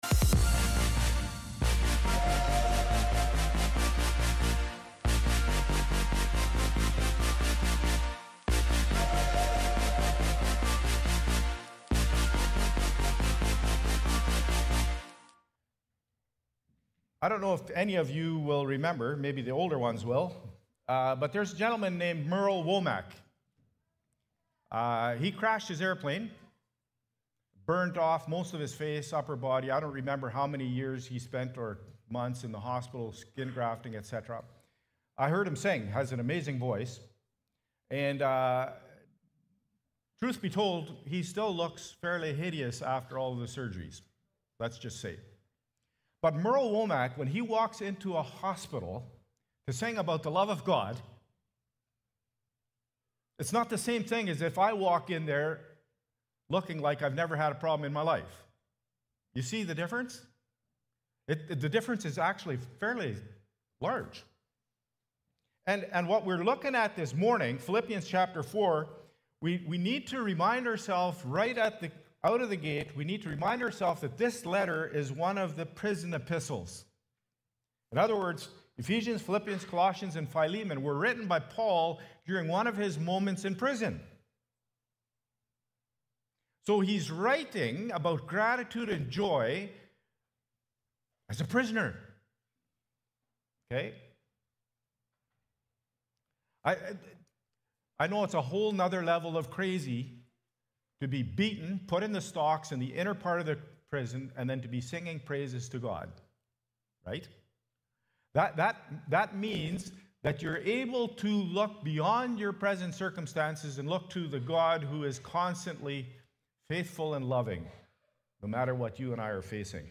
October-20-Worship-Service.mp3